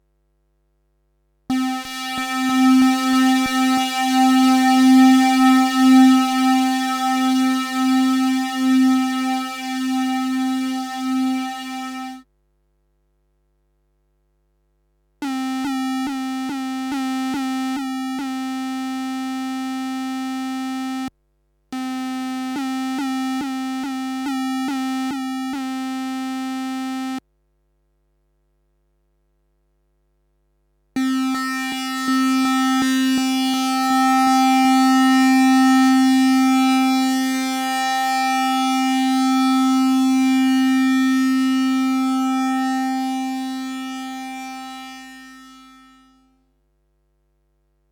Demo
First we hear 8 times the same note with a long release tail so you can hear how out of tune it is. You hear 8 voices with 2 oscillators each, so 16 oscillators.
Then I tune oscillator 1 across all 8 voices, followed by oscillator 2. Then I play the same repeated note with a long release tail and we hear almost no beating.